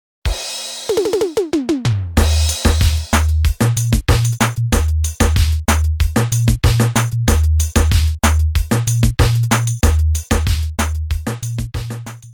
Drumcomputer
Linndrum 2
Reggae Dancehall Style
lin_dancehall.mp3